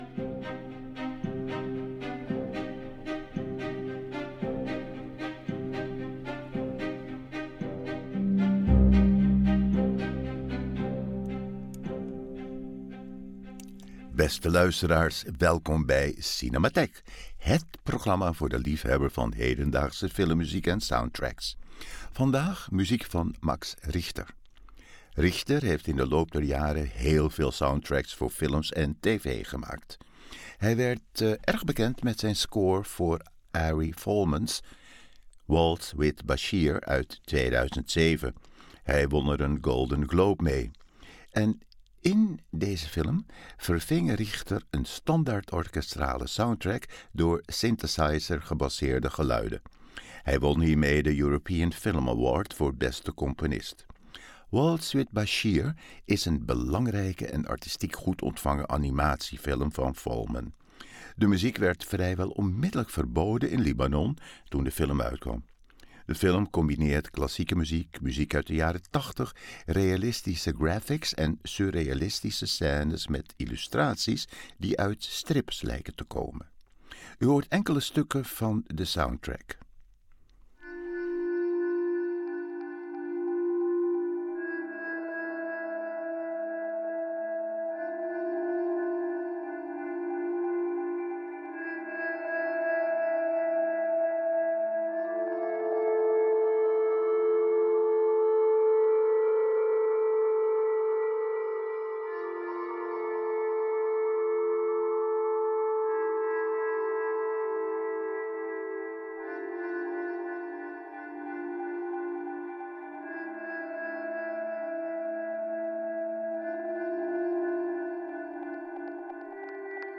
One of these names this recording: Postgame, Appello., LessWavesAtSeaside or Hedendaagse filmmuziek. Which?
Hedendaagse filmmuziek